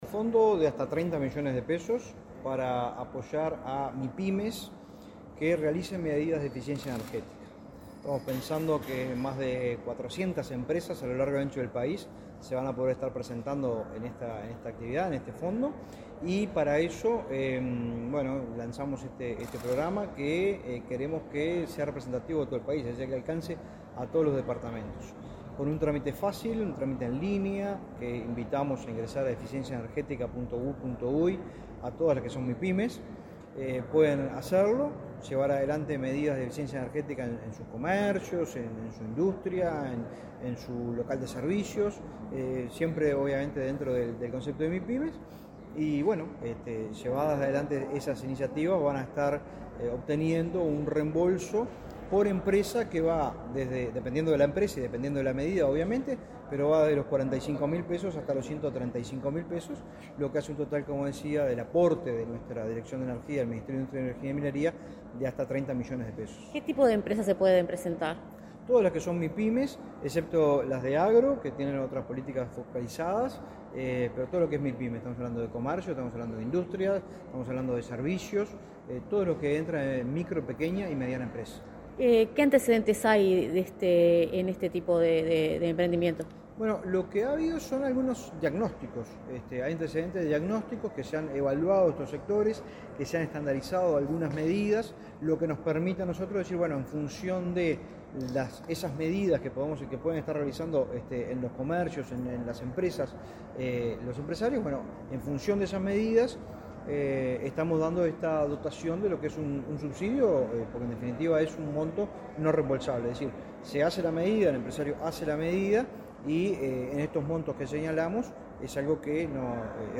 Entrevista al director nacional de Energía, Fitzgerald Cantero